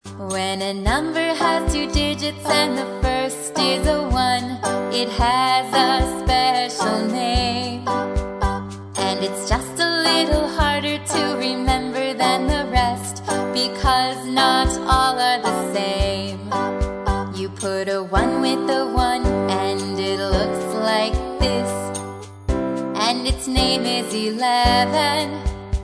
Vocal mp3 Track